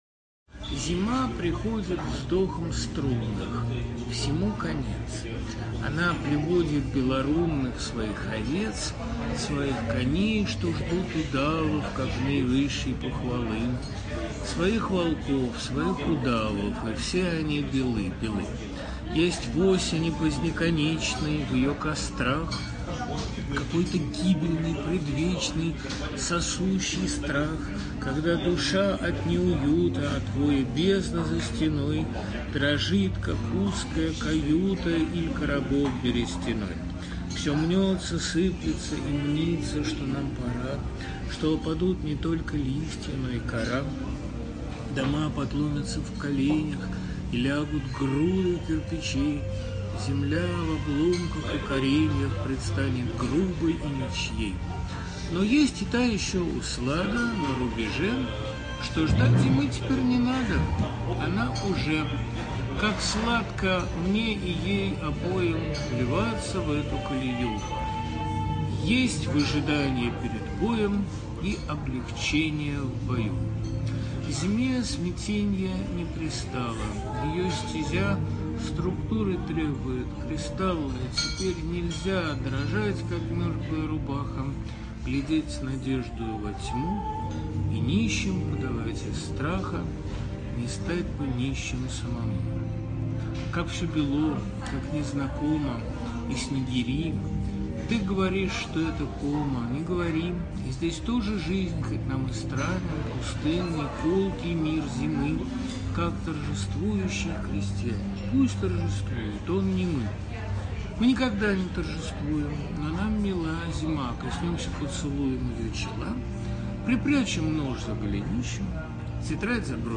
Bykov-stihotvorenie-Nachalo-zimy-stih-club-ru.mp3